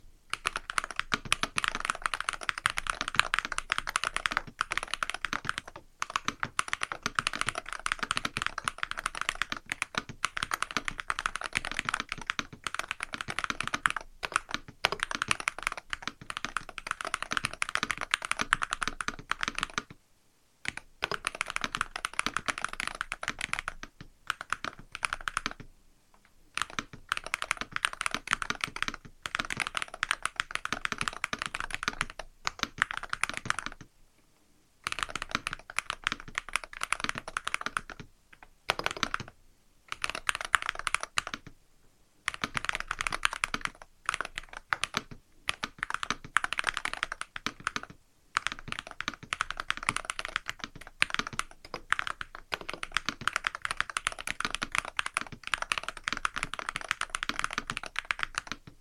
I recorded the SK75 TMR using a Maono PD200W microphone:
In terms of sound, the SK75 TMR sounds like a mix between a gasket mount and a tray mount: it has the deeper, “thocky” sound of a gasket mount, but it also has a more pronounced high-end component to it that makes it sound crispier. It is audible in a silent room, but not so much so that it becomes overbearing; this is due to the usage of PORON foam under the plate and a PET film and EPDM foam on the bottom to reduce the sound.